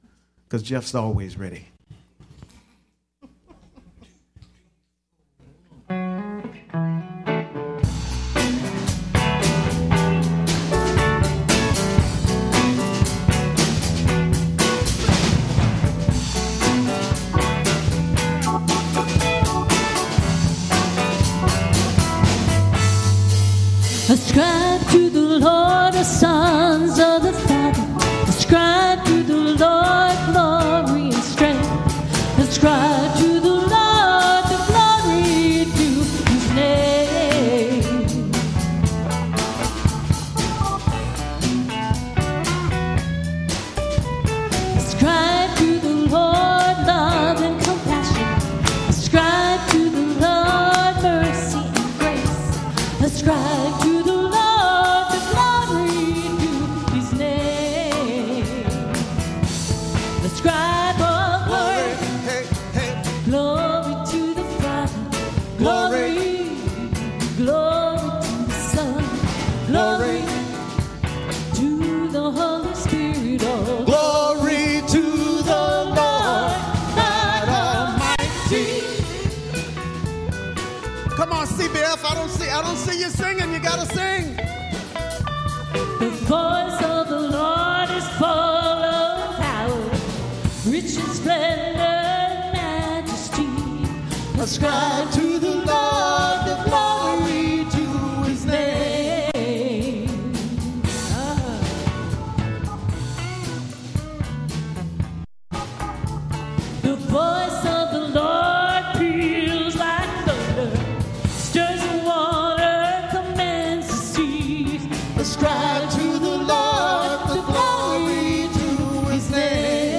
Live recording Of Unchained Community Bible Fellowship 10-26-14
Front of House mix with focus of vocals